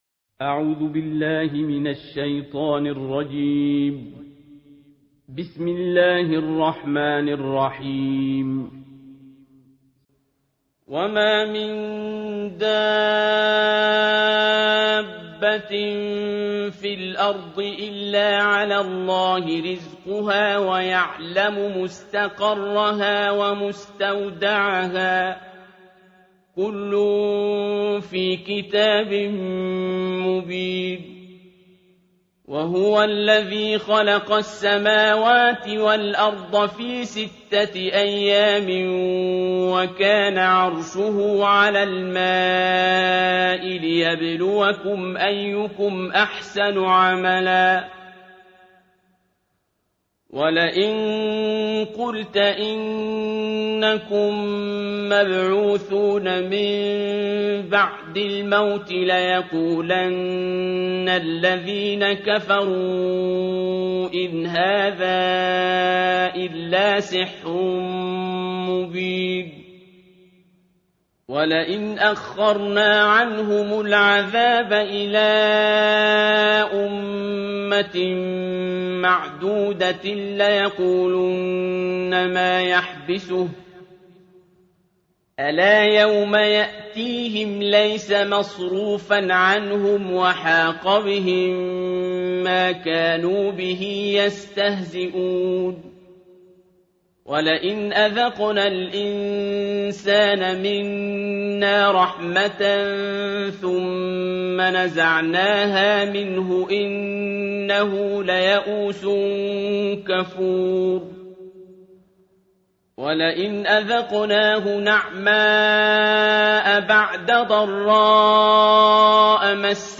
ترتیل جزء ۱2 قرآن کریم + دانلود